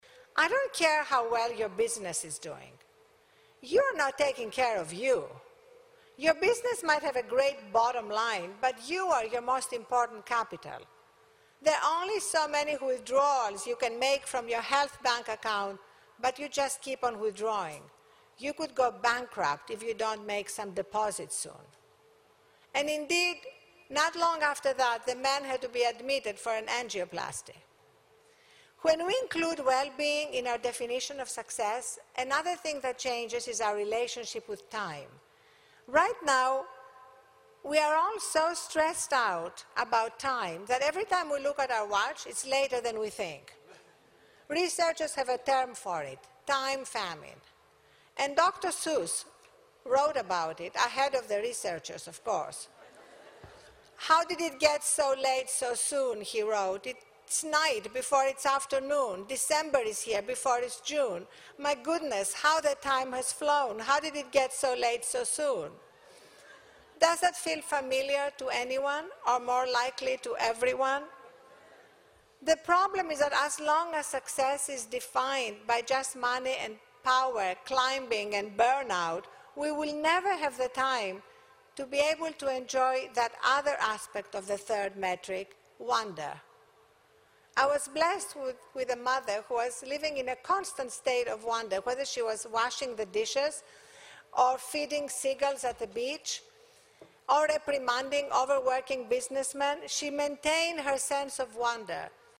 公众人物毕业演讲 第319期:阿丽安娜.哈芬顿2013史密斯学院(9) 听力文件下载—在线英语听力室